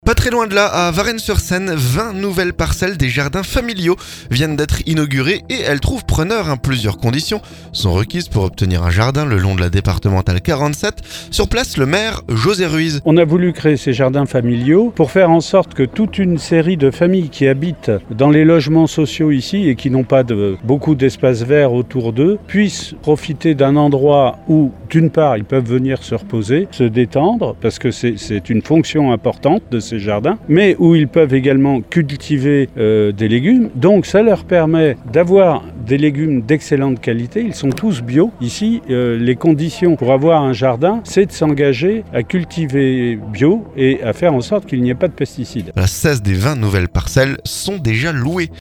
Plusieurs conditions sont requises pour obtenir un jardin le long de la départementale 47. Sur place, le maire José Ruiz.